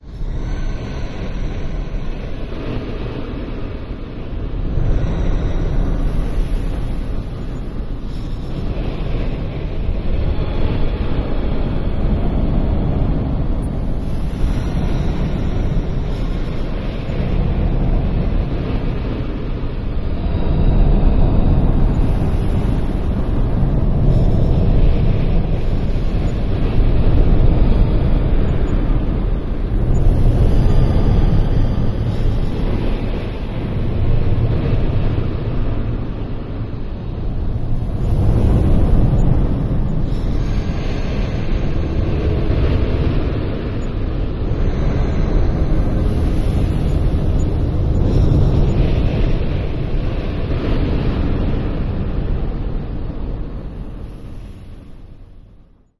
恐怖电影的背景效果
描述：恐怖电影的背景效果.令人毛骨悚然，令人害怕。产生一些恐怖的尖叫.
标签： 动乱 恐怖 电影 背景声 纷飞 尖叫 环境音 合成音 科幻
声道立体声